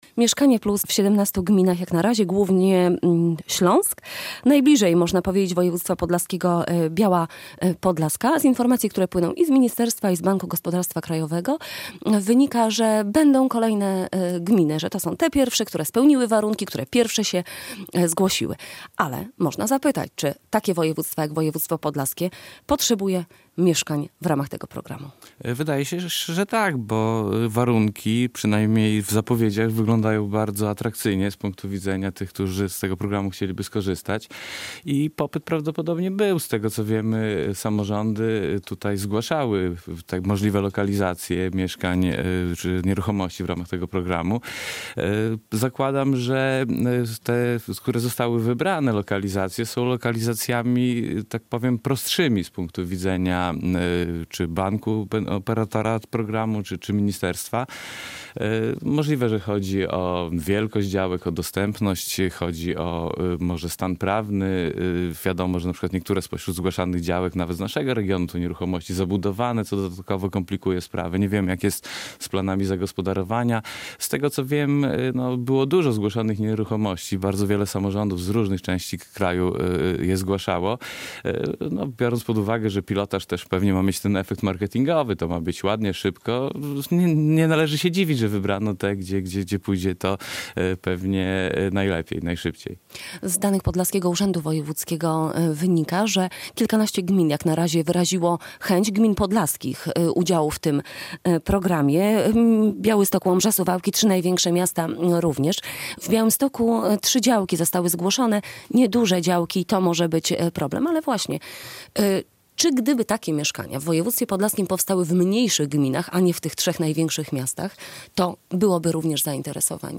ekonomista